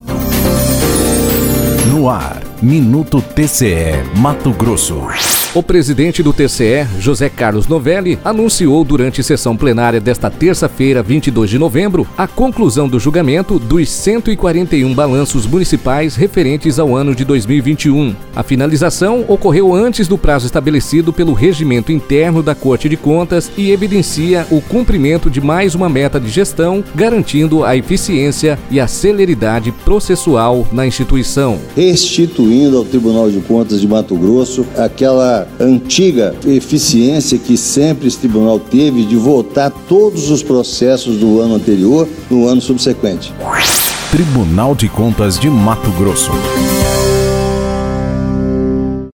Sonora: José Carlos Novelli – conselheiro presidente do TCE-MT